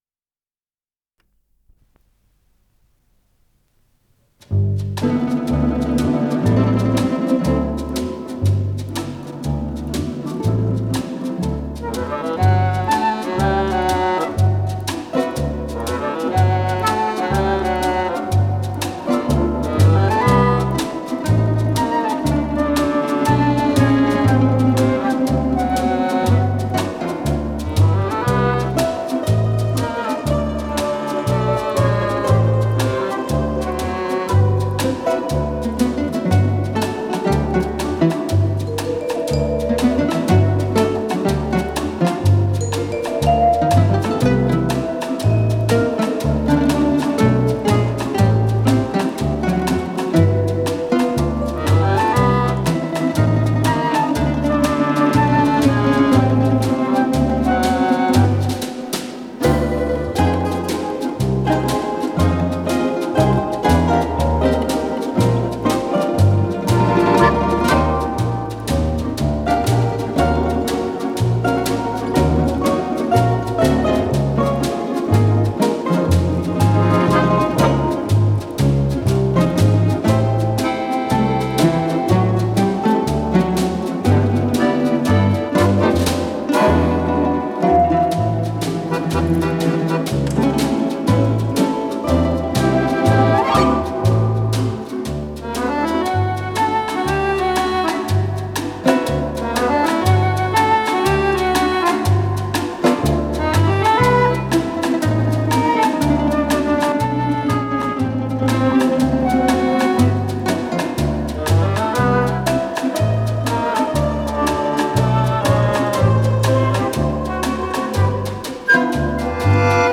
Скорость ленты38 см/с
ВариантДубль моно